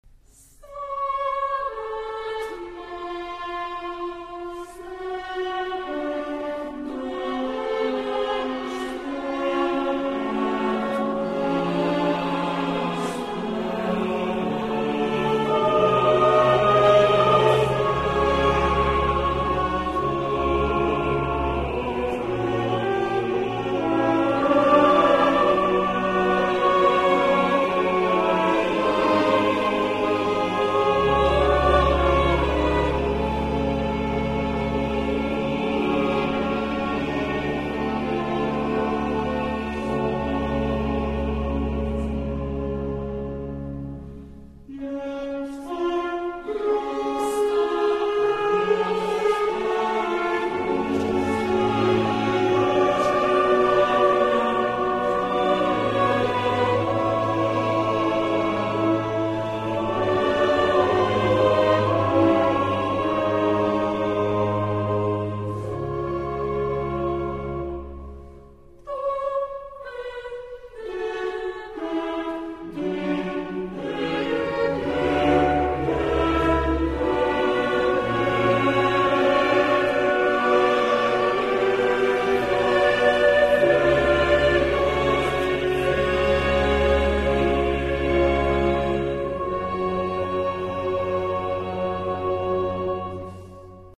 Coro della radio Svizzera - Diego Fasolis & Aura Musicale Budapest - René Clemencic
ATTENTION ! Dans cet enregistrement, on utilise le "diapason baroque" (La415).
On l'entend donc environ un demi-ton en dessous du diapason actuel (La440) que l'on emploiera et qui est celui des fichiers midi et virtual voice.